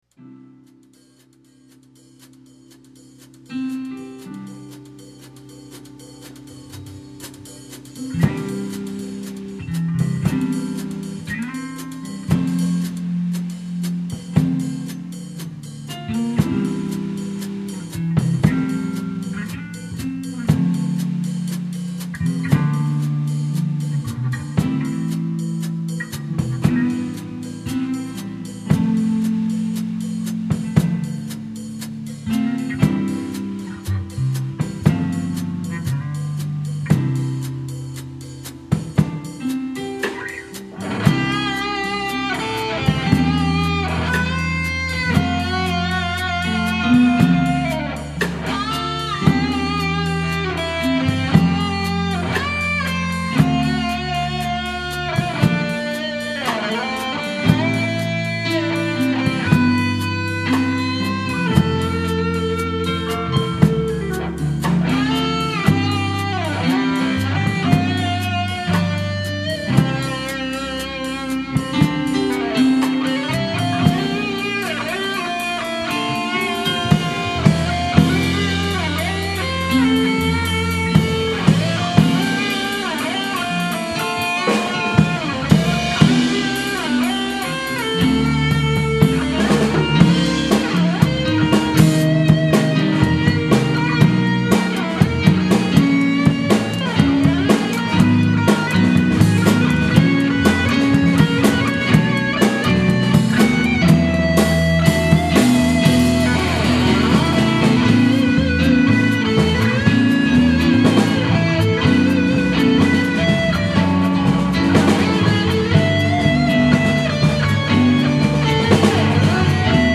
밴드에서 카피했던 거고 거의 반 장난으로 연주했던 버젼이지만 재미있을듯 해서 올립니다